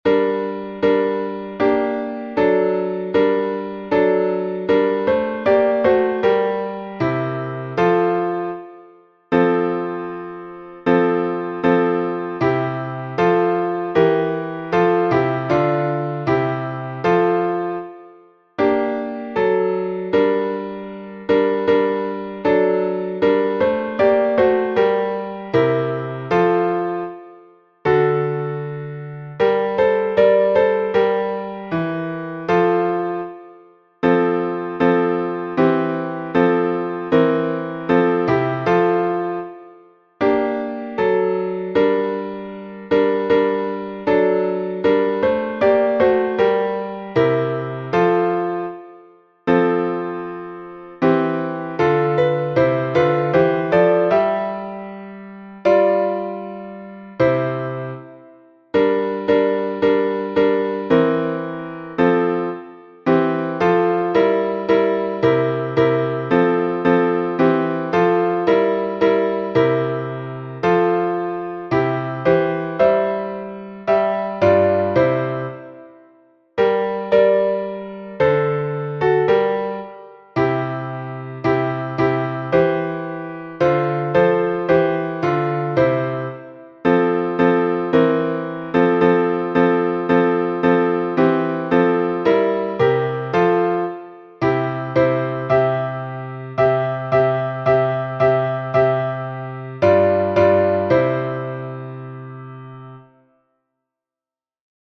Киевский распев